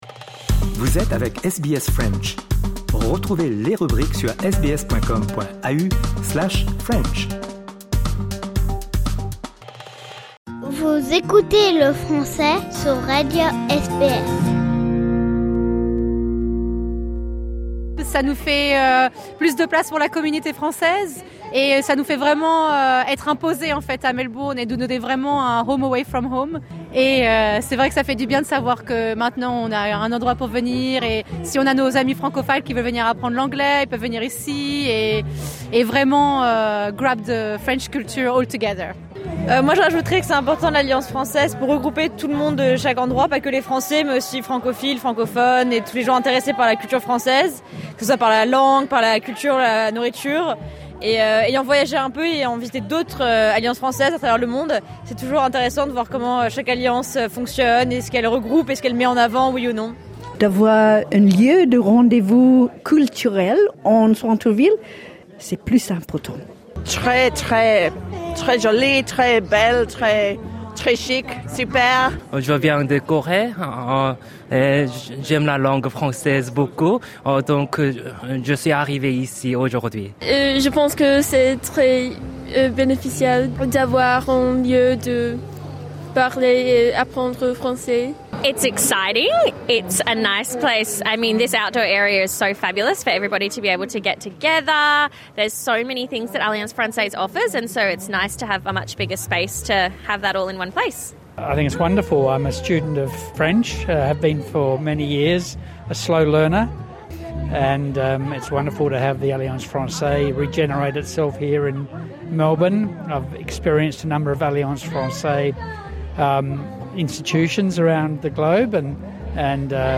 A l’occasion de l’ouverture des nouveaux locaux de l’Alliance française de Melbourne, nous sommes allés à la rencontre des visiteurs qui parlent et qui apprennent le français et leurs avons posé une question : que pensez-vous du nouveau french hub en plein cœur du CBD ?